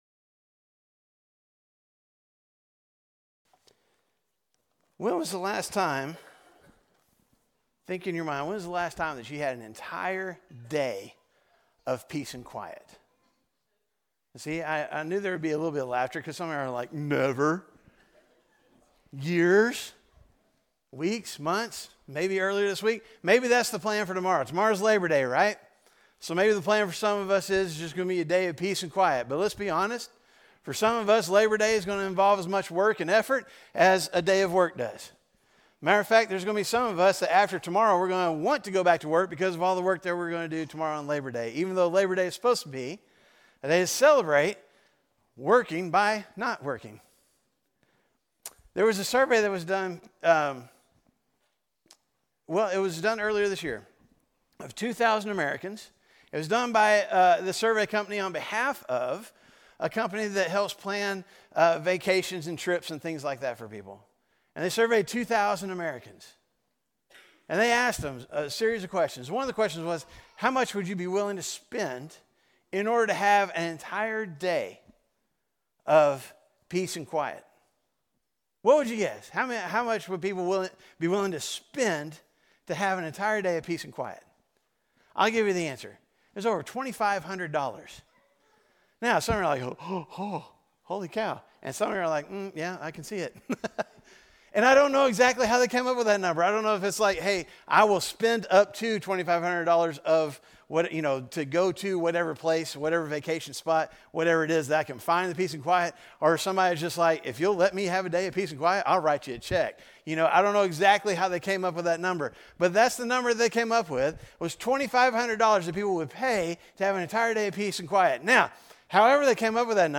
A message from the series "Detox."